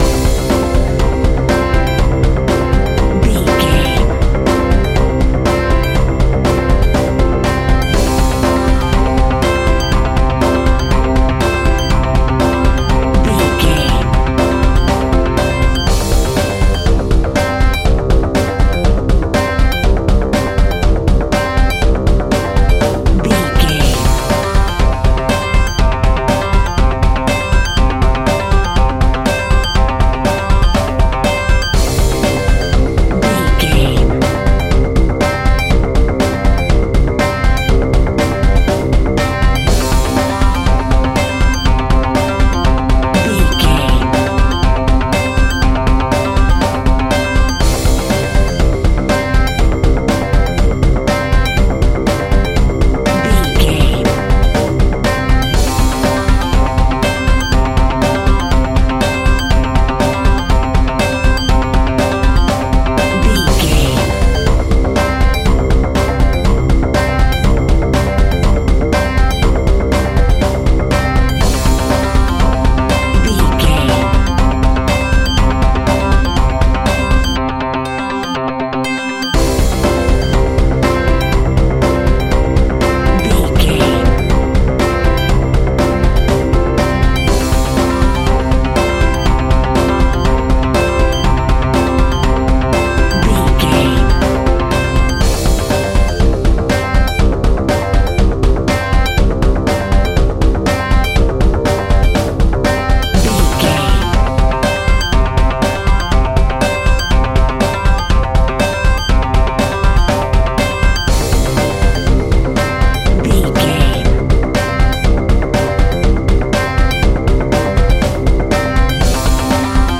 Ionian/Major
pop rock
indie pop
fun
energetic
uplifting
drums
bass guitar
piano
hammond organ
synth